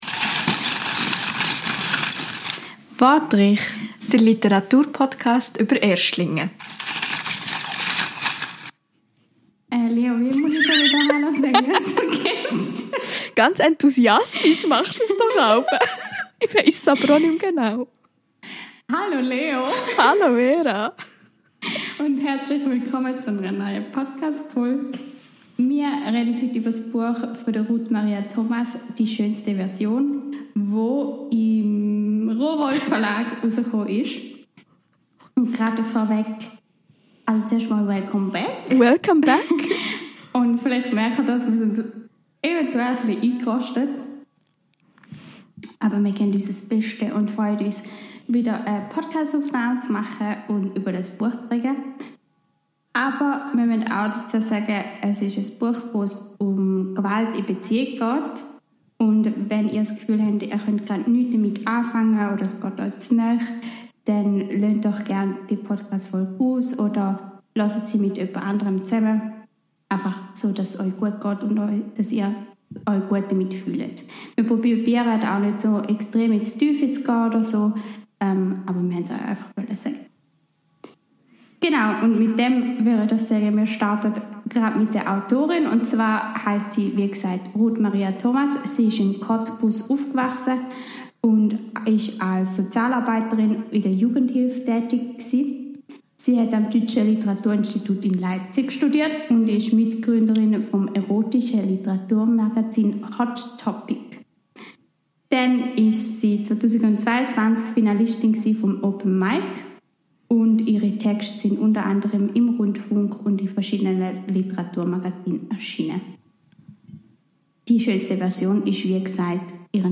In dieser Folge sprechen wir über das Frauwerden und das idealisierte Bild davon. Zudem ist Gewalt in Beziehungen ein Thema. Ps. Wir hatten bisschen Tonprobleme bei dieser Folge und entschuldigen uns dafür.